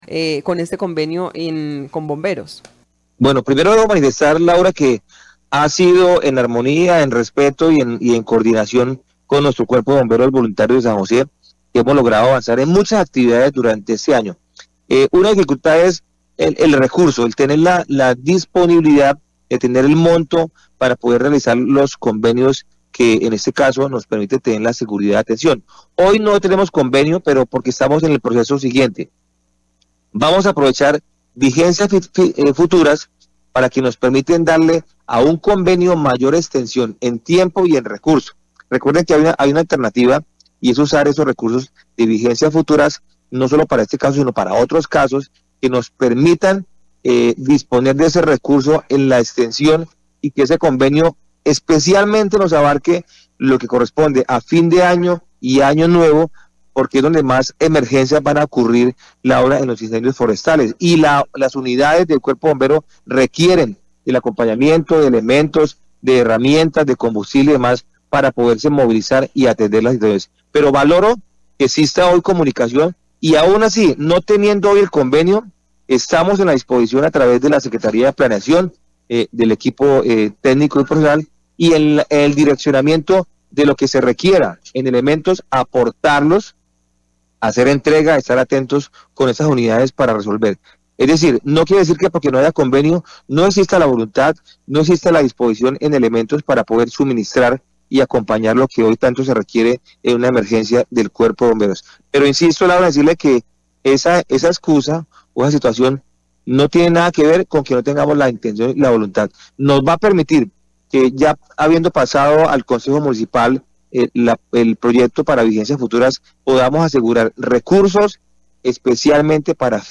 El alcalde Willy Rodríguez anunció avances y desafíos en temas culturales y de servicios públicos durante un reciente diálogo.